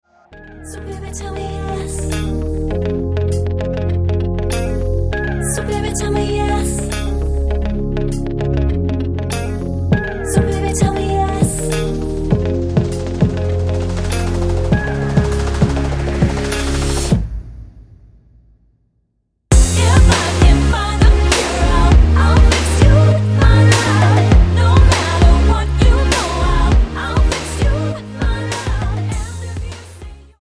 Tonart:Ab mit Chor